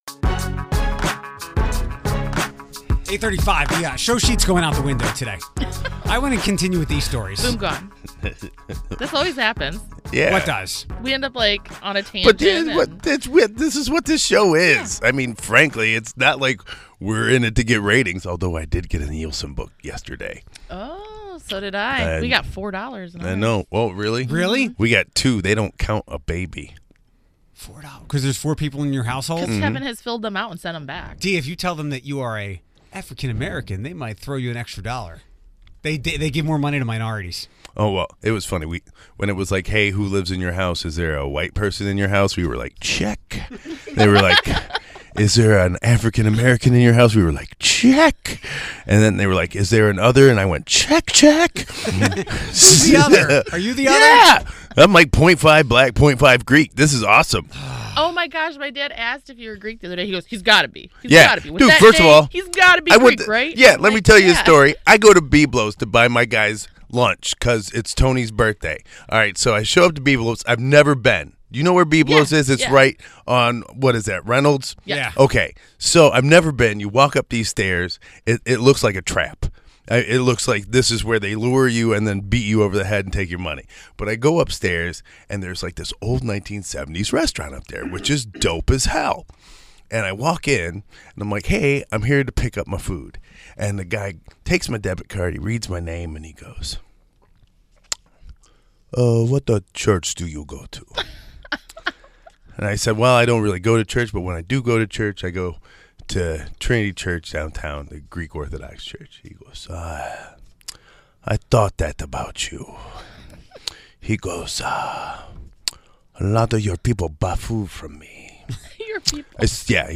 We talked, and we laughed.